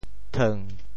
“捅”字用潮州话怎么说？